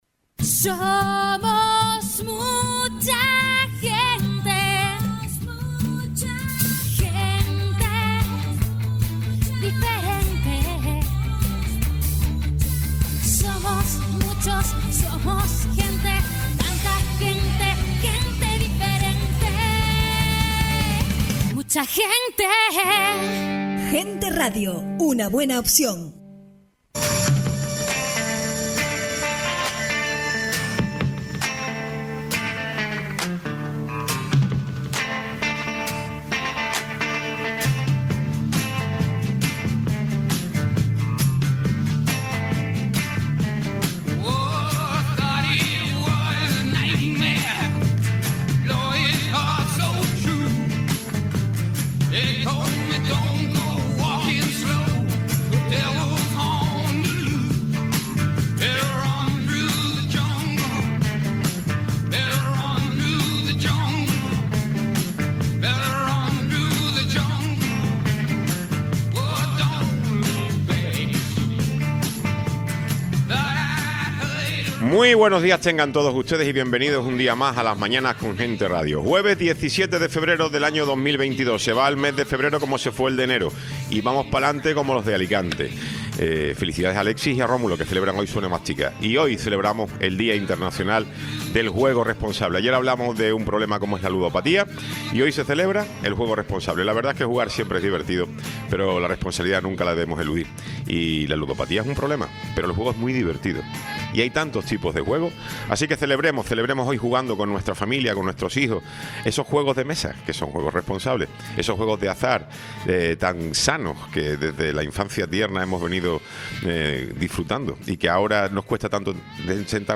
Tiempo de entrevista con Tomás García, Director Insular de Carreteras del Cabildo de Tenerife